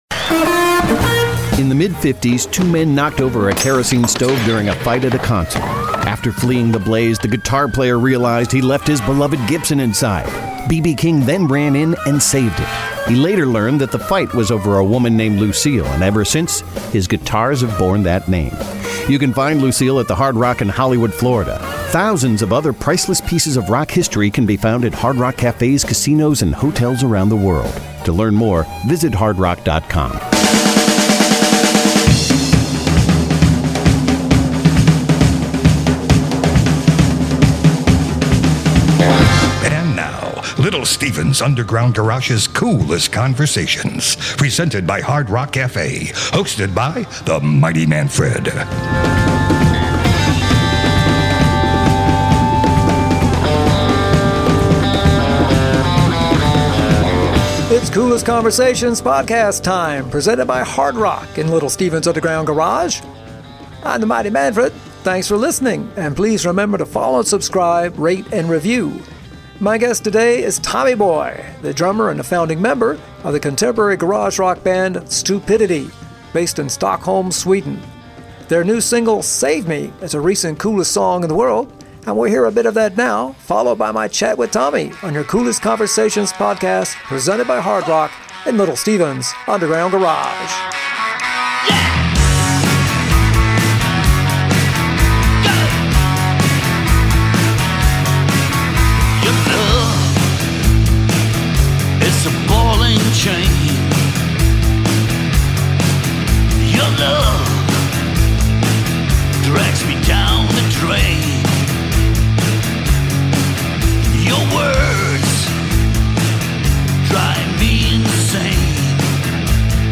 Coolest Conversations